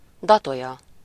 Ääntäminen
Ääntäminen France: IPA: [dat] Haettu sana löytyi näillä lähdekielillä: ranska Käännös Ääninäyte Substantiivit 1. datolya Suku: f .